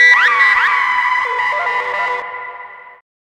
OLDRAVE 4 -L.wav